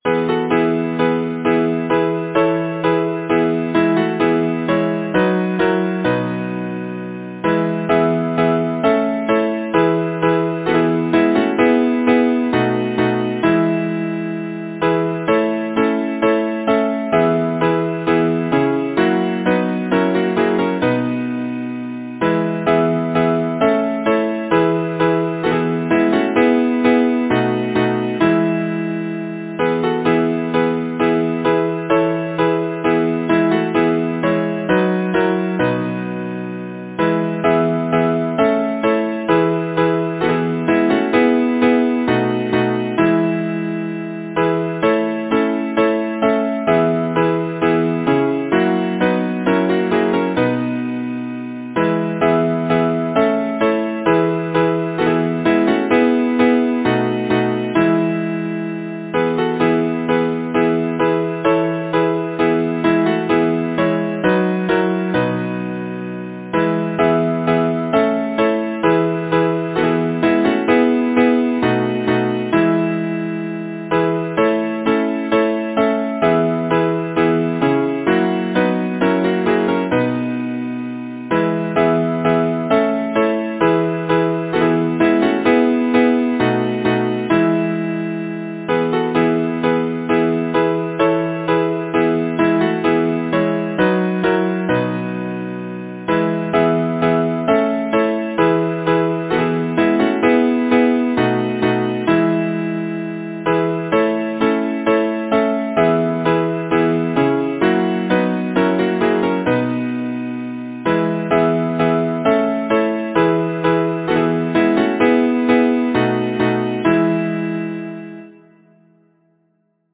Title: Song of the woods Composer: Stephen Jesse Oslin Lyricist: Number of voices: 4vv Voicing: SATB Genre: Secular, Partsong
Language: English Instruments: A cappella